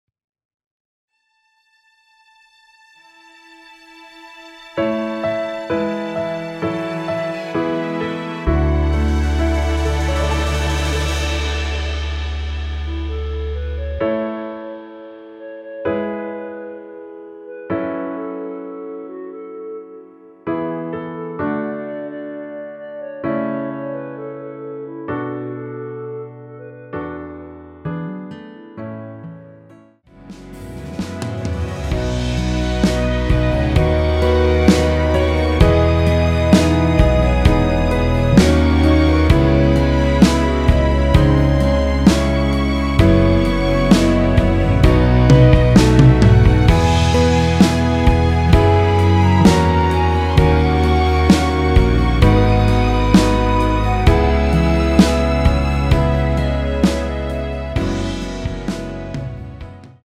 원키 멜로디 포함된 MR입니다.(미리듣기 확인)
멜로디 MR이라고 합니다.
앞부분30초, 뒷부분30초씩 편집해서 올려 드리고 있습니다.
중간에 음이 끈어지고 다시 나오는 이유는